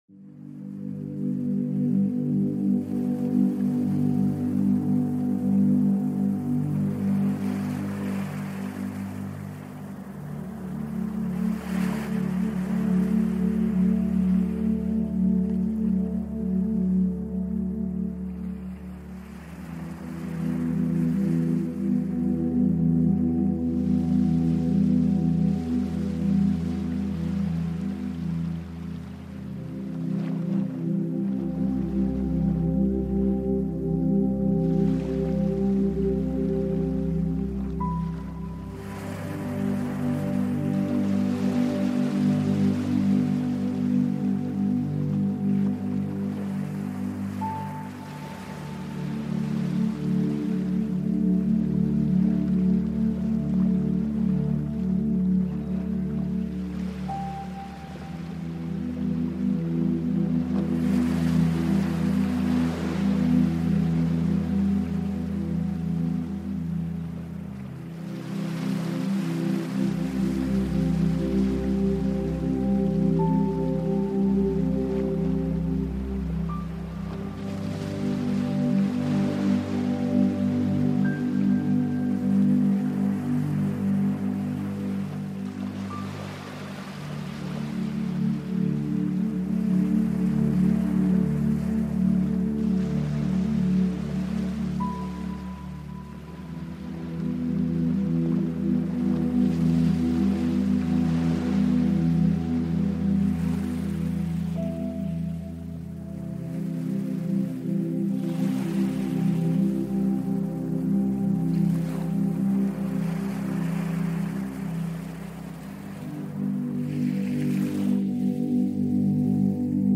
Ainsi, une fois que tu appuies sur lecture, rien ne vient interrompre ton immersion. Aucun bruit parasite, aucune coupure soudaine.